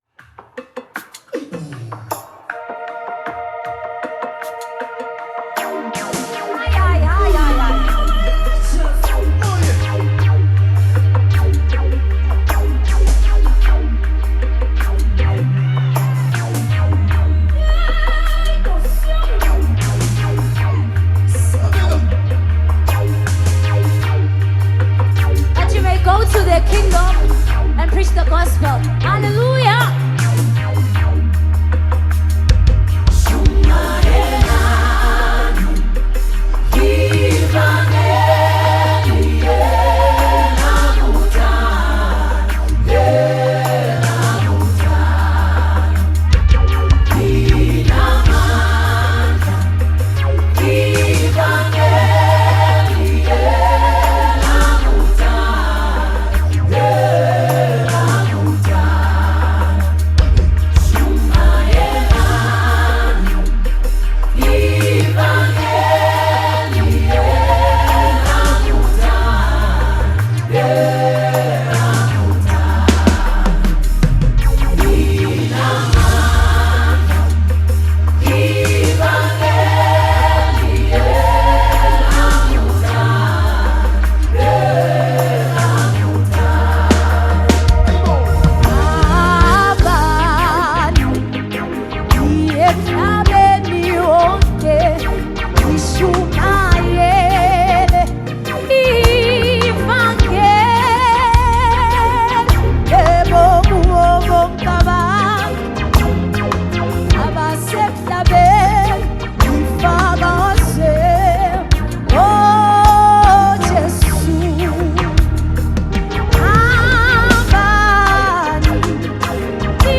South African Gospel
Genre: Gospel/Christian